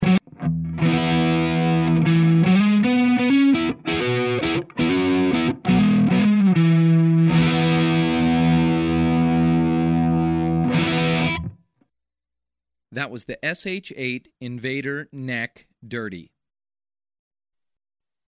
• Biedt als contrast een warmer en iets meer beteugeld basissound voor in de halspositie.
• Levert bij vervorming extra sustain en een dik, meer vervormd randje aan het geluid.
Seymour Duncan SH-8n Invader Halselement: dirty sound Audio Onbekend
sh-8n_neck_dirty.mp3